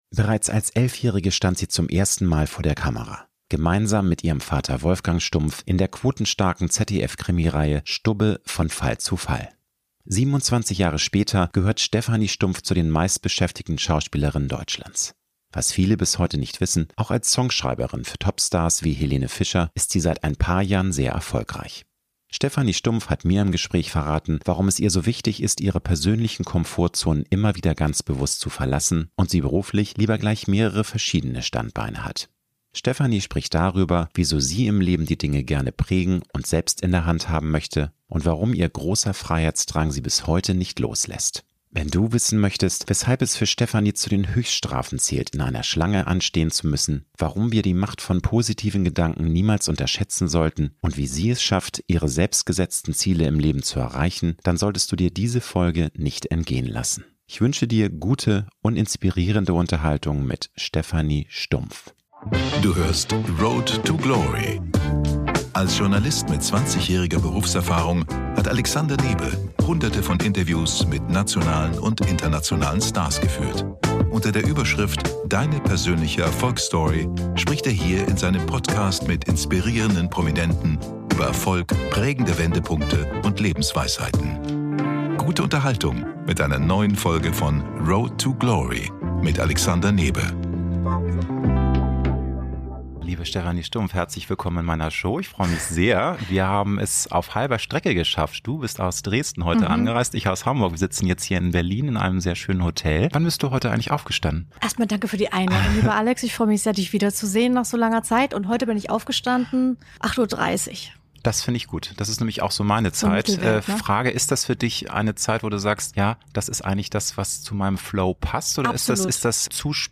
Stephanie Stumph hat mir im Gespräch verraten, warum ihr es so wichtig ist, ihre persönlichen Komfortzonen immer wieder ganz bewusst zu verlassen und sie beruflich lieber gleich mehrere verschiedene Standbeine hat. Stephanie spricht darüber, wieso sie im Leben die Dinge gerne prägen und selbst in der Hand haben möchte und warum ihr großer Freiheitsdrang sie bis heute nicht loslässt.